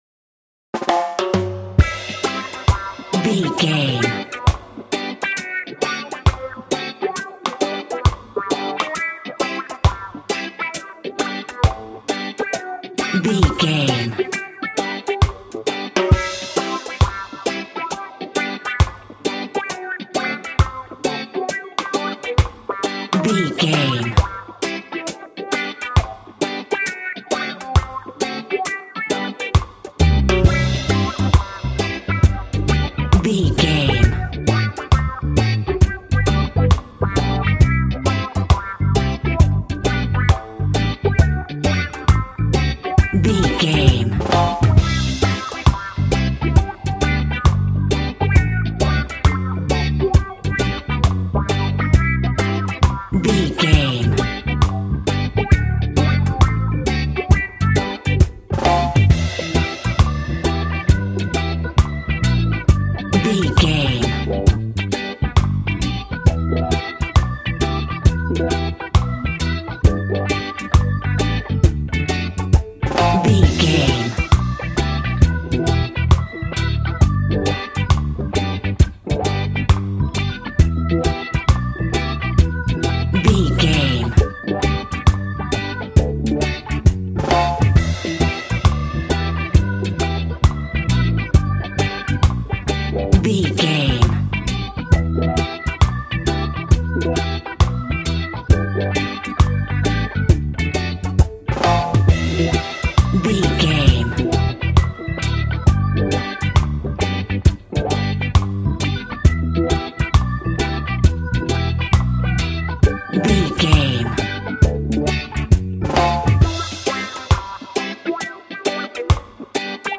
Ionian/Major
cheerful/happy
mellow
fun
drums
electric guitar
percussion
horns
electric organ